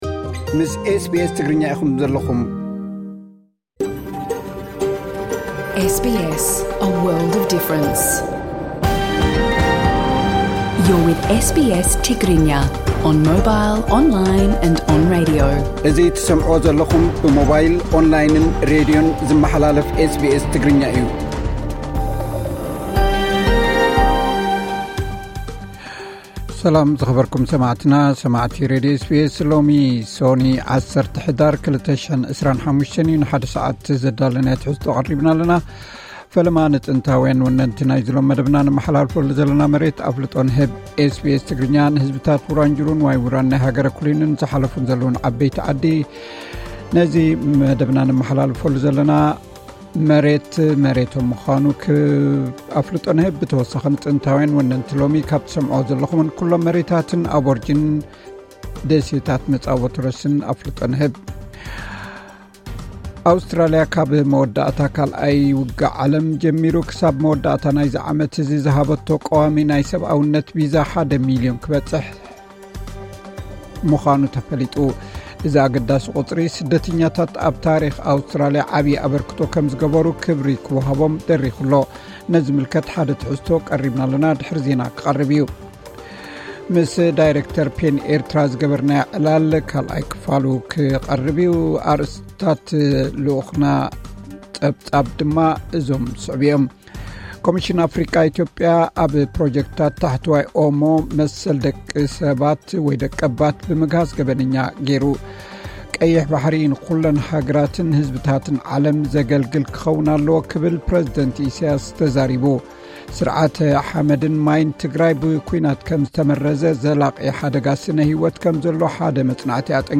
ቀጥታ ምሉእ ትሕዝቶ ኤስ ቢ ኤስ ትግርኛ (10 ሕዳር 2025)